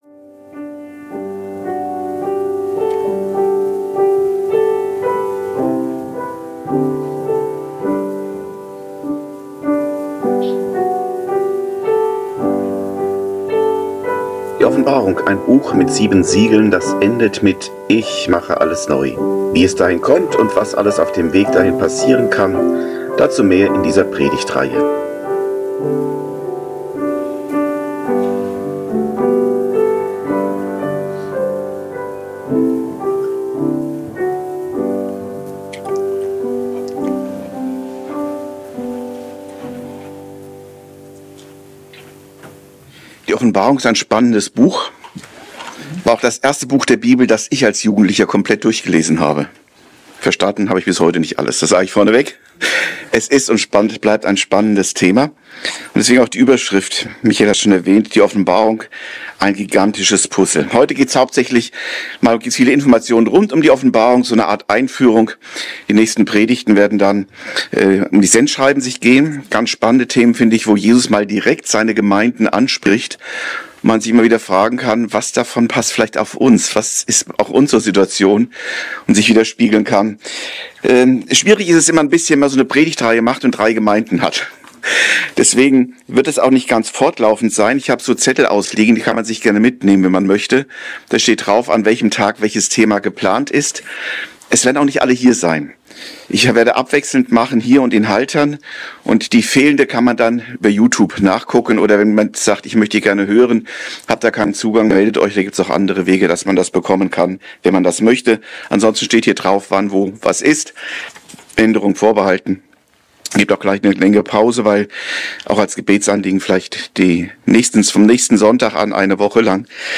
01 Die Offenbarung - ein gigantisches Puzzel! ~ Predigten u. Andachten (Live und Studioaufnahmen ERF) Podcast